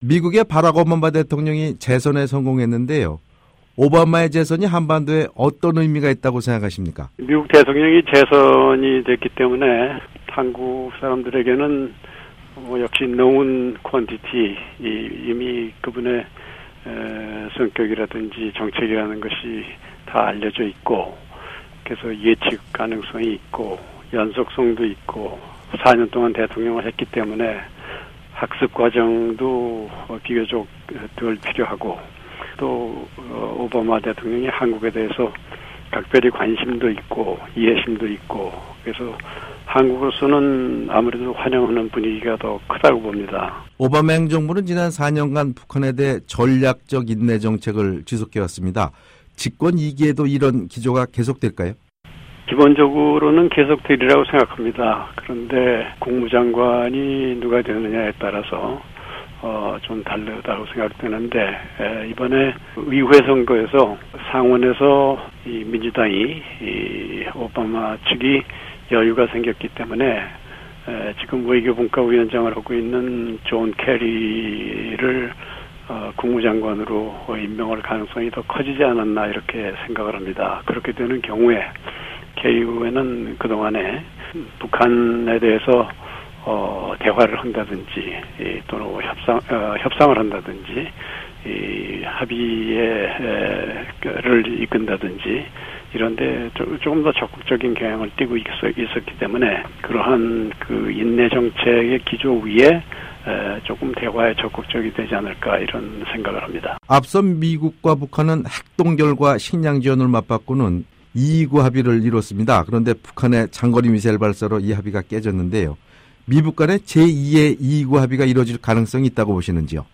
[인터뷰] 한국 한승주 전 외무장관